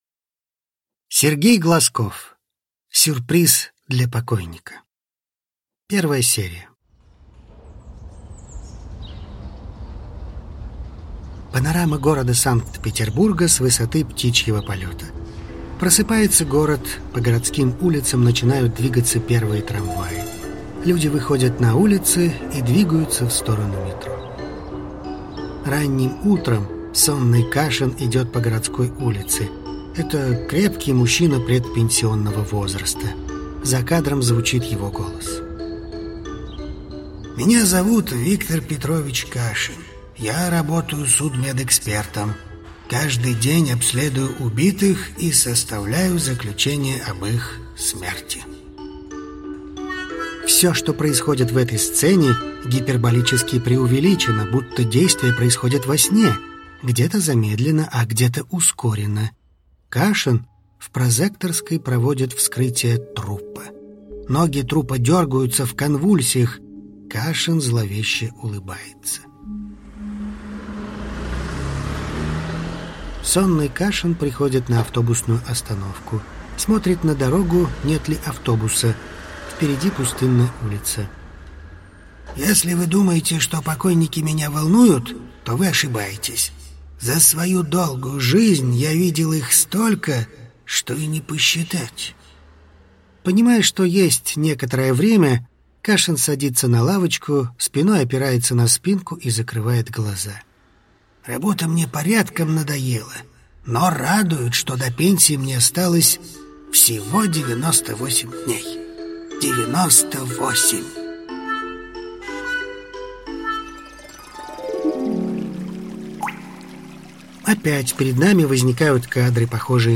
Аудиокнига Сюрприз для покойника | Библиотека аудиокниг
Прослушать и бесплатно скачать фрагмент аудиокниги